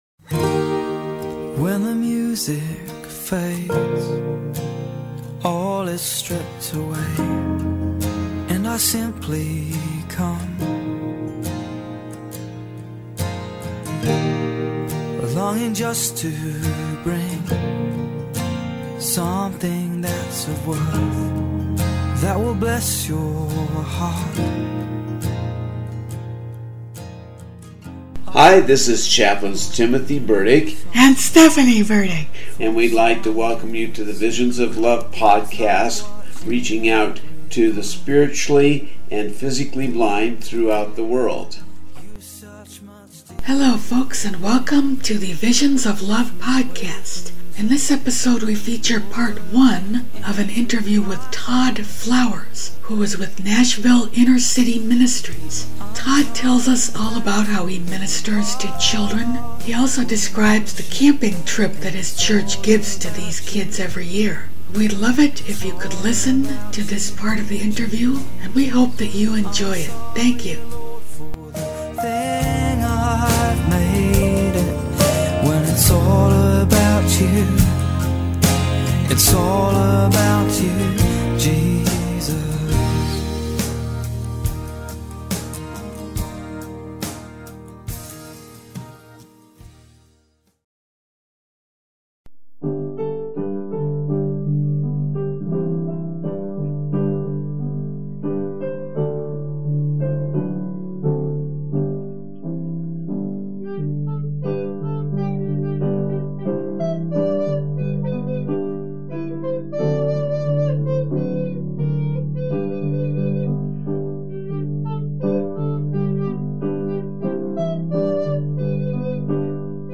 In today's episode we begin an interview